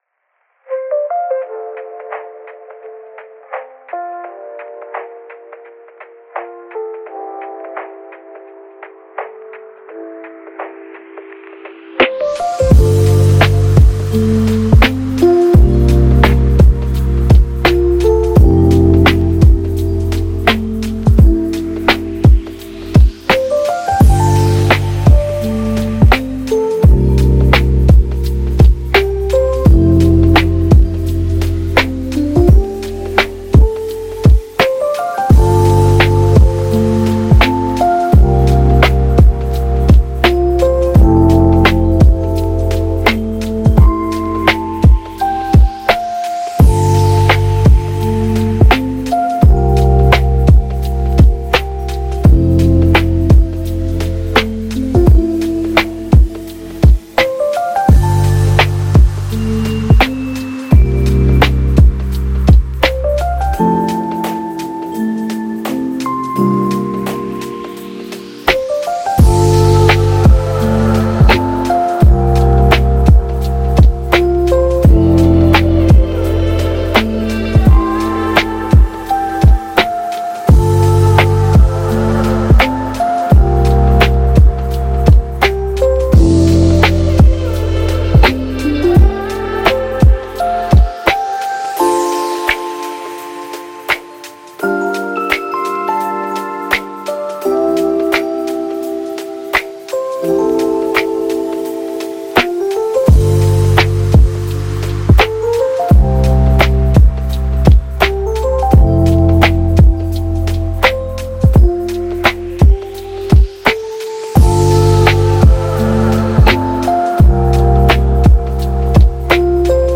Lofi Interview